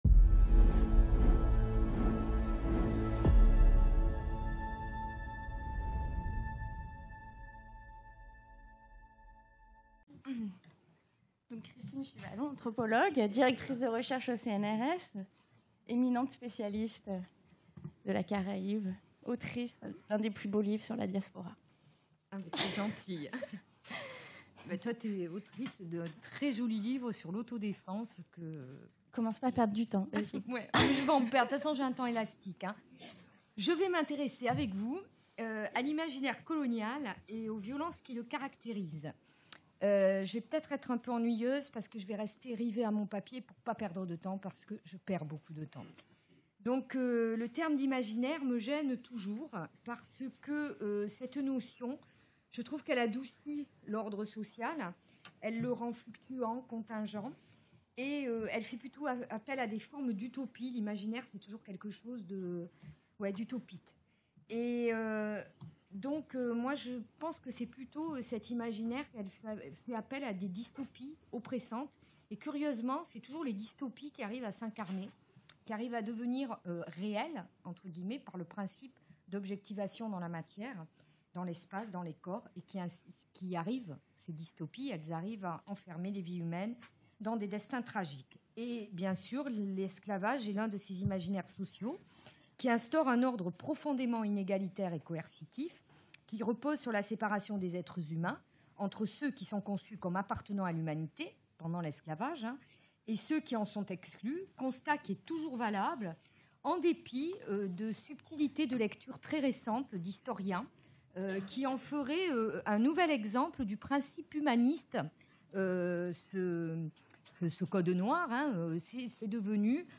Journée d’étude Mondes Caraïbes et Transatlantiques en Mouvement - Mercredi 3 octobre Focus sur les révoltes anticoloniales (Martinique – Guadeloupe – Madagascar) La violence est intrinsèque à la colonisation qui est l’une des formes de domination les plus coercitives allant bien au-delà de la fabrique du consentement, même si cette dernière intervient dans les processus de stabilisation de la relation d’assujettissement. Cette violence s’exerce sur les corps de manière brutale, dans l’ensemble des processus de disciplinarisation qu’exige le maintien d’un ordre essentiellement conquérant et hiérarchique, confinant à la déshumanisation et à l’animalisation du colonisé.